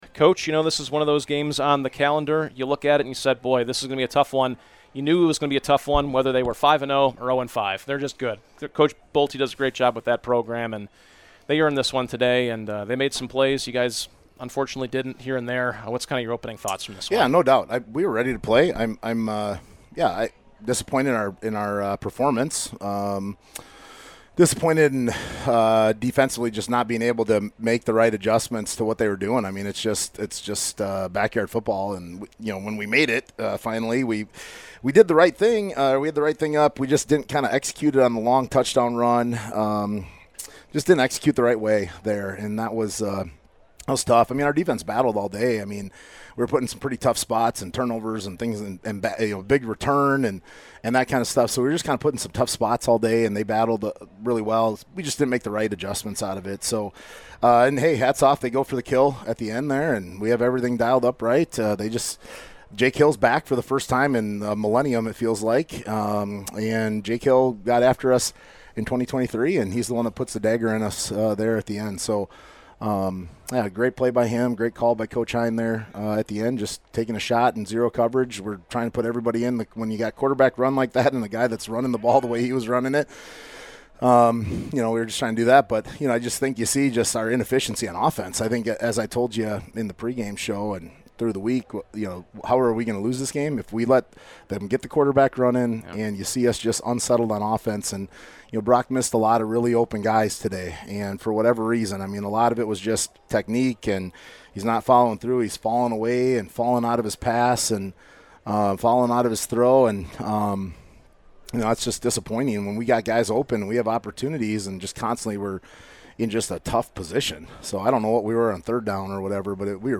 the TUF Fitness Wolves Wrap post-game show